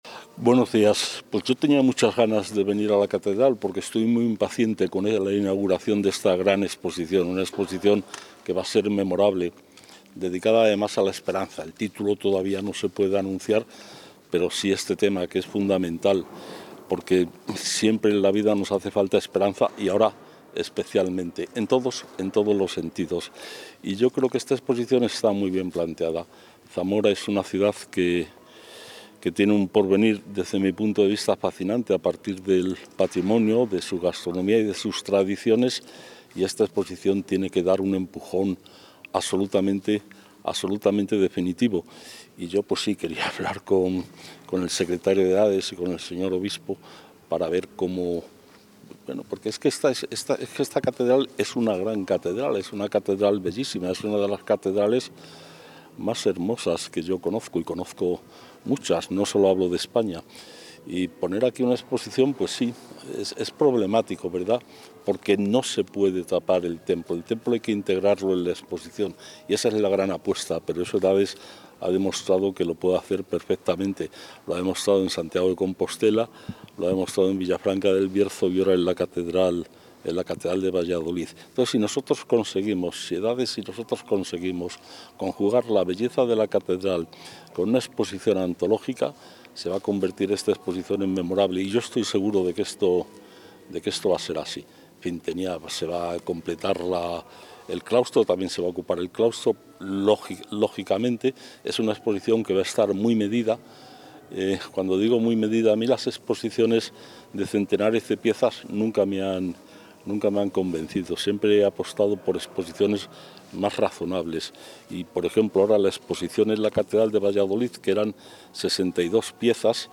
Declaraciones del consejero.